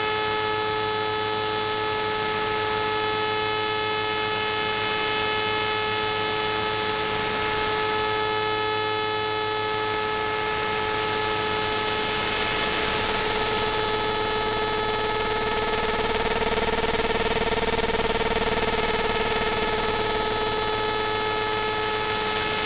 Radar_430Hz_and_oddity.mp3